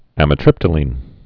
(ămĭ-trĭptə-lēn)